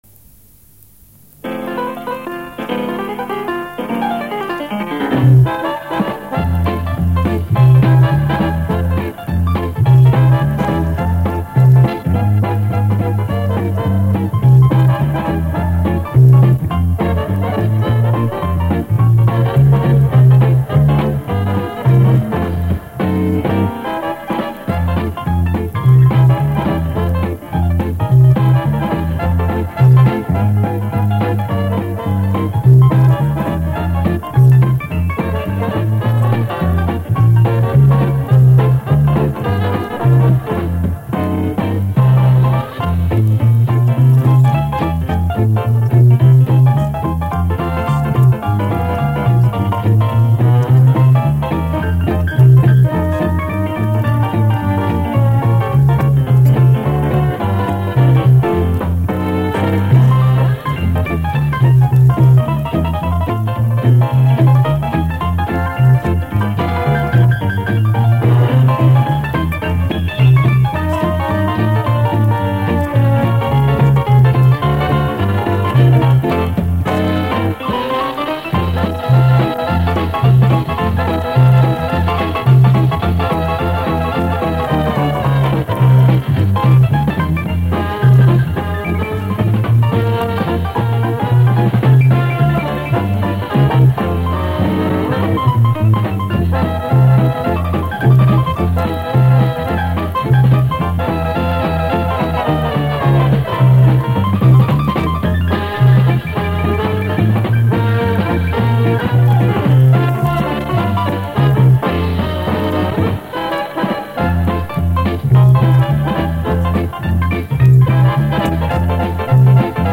Все они с катушек магнитных лент, записанные в 70е года.
2. Произведение в исполнении эстрадно-джазового коллектива.
2.-estr-djaz.mp3